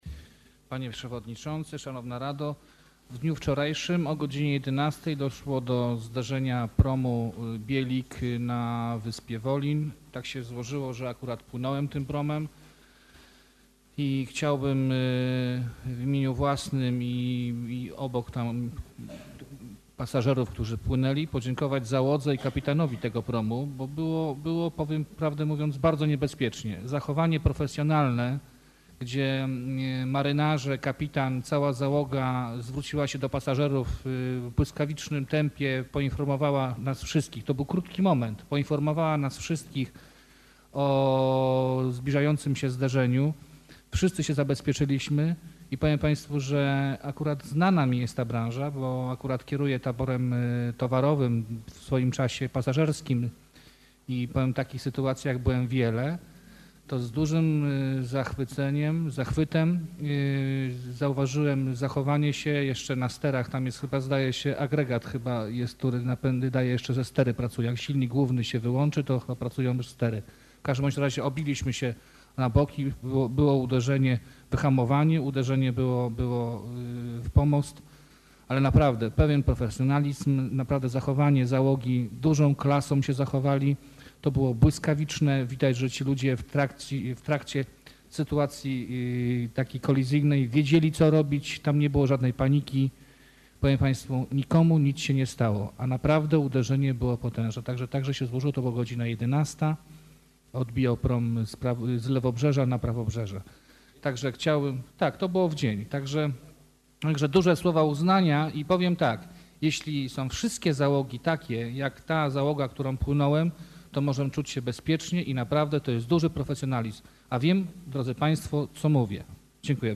O zdarzeniu poinformował podczas czwartkowej sesji rady miasta Sławomir Nowicki.